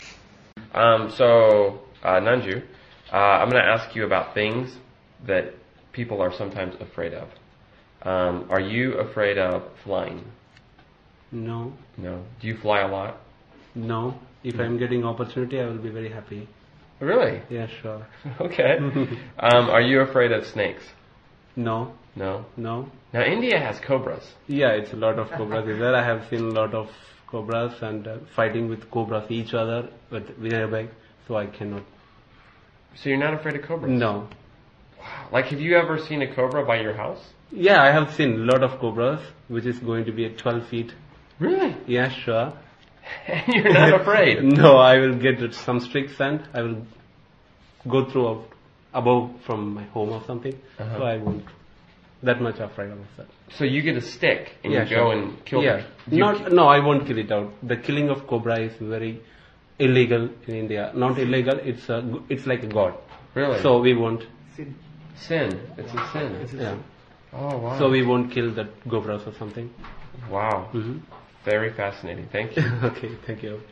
英语初级口语对话正常语速04：恐惧（MP3+lrc）